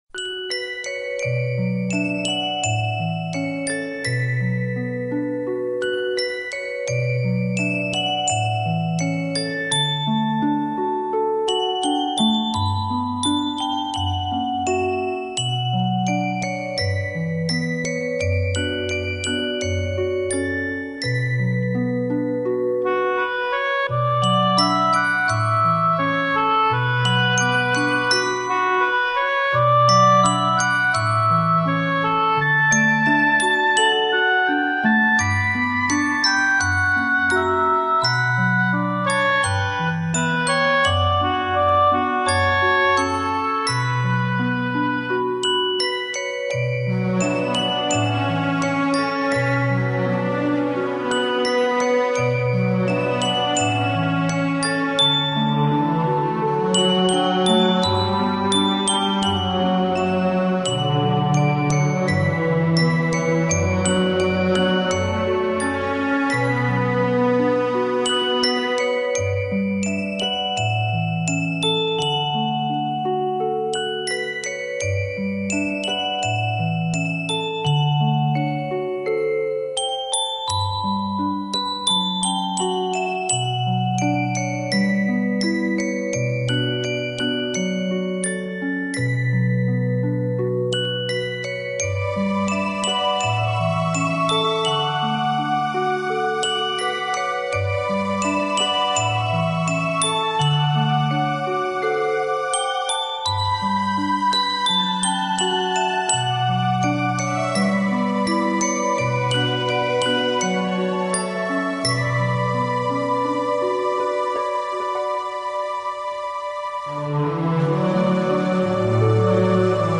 【纯音乐】
灵动的水晶琴音将各式经典名曲演绎得如同回响于天际间的天籁之声， 清脆、悦耳的琴似细雨滴水晶诱发出的奇妙韵律，
纯净、剔透、清脆、轻盈，映照人世的匆匆，折射都市的喧嚣，安抚虚空的心灵。 空灵、飘渺的共鸣声，烘托出万籁俱寂。